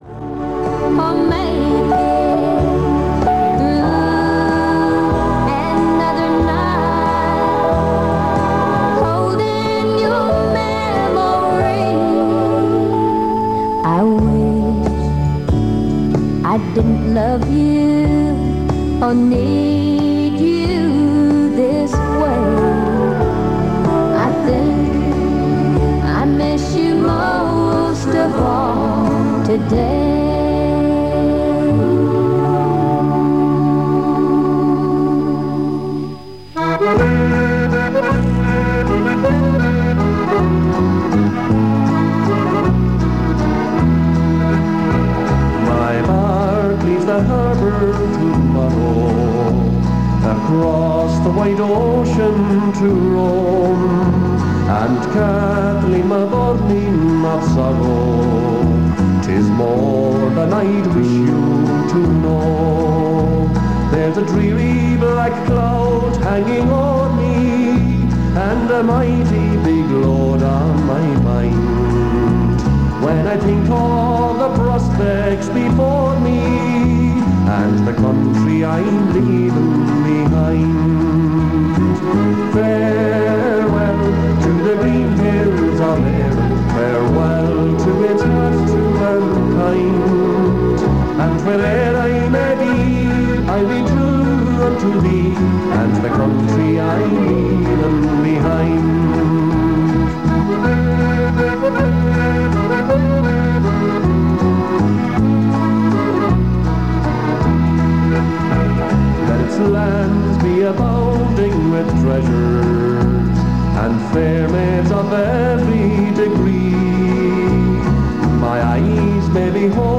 Treble TR (sometimes written TTTR) was a country and Irish pirate station broadcasting from Dublin from the spring of 1981 until the end of 1988.
Adverts are heard from small and medium-sized businesses around town.